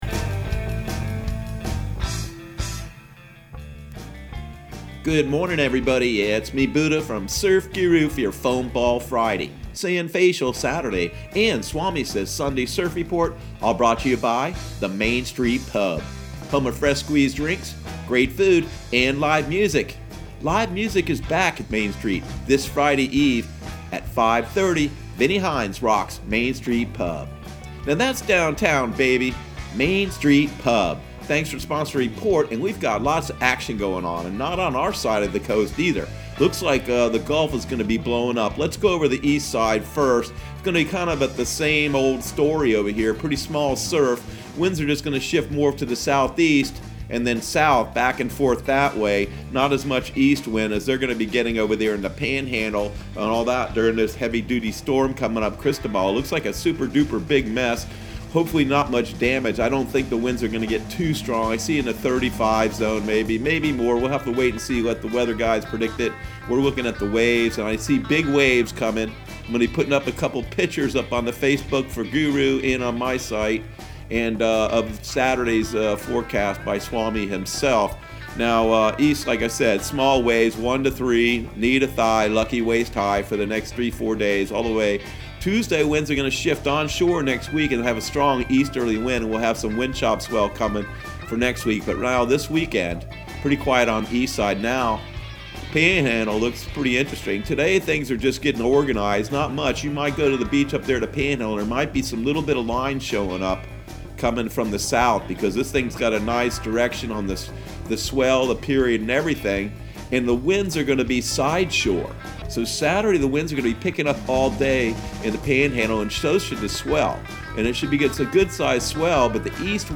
Surf Guru Surf Report and Forecast 06/05/2020 Audio surf report and surf forecast on June 05 for Central Florida and the Southeast.